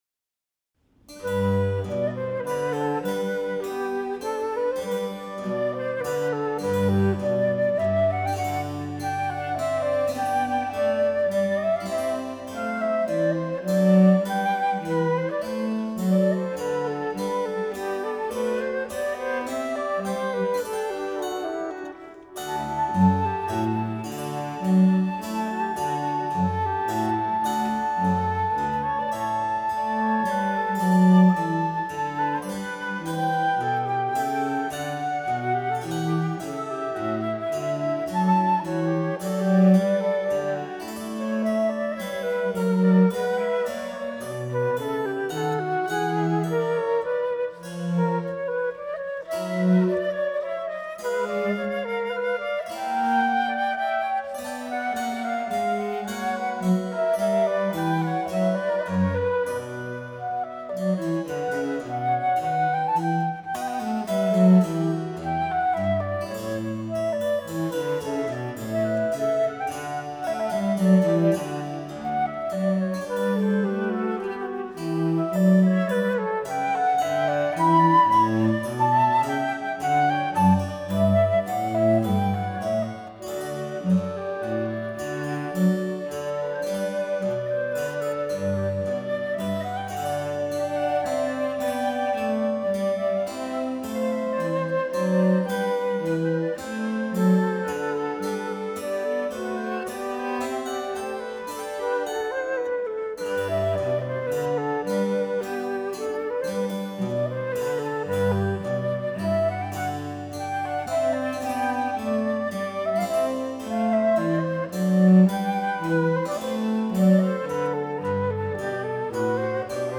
Incamto ensemble | Live recording | InCamTo
flauti
clavicembalo
violoncello
Chiesa di Santa Chiara, Torino